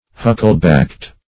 Huckle-backed \Huc"kle-backed`\, a. Round-shoulded.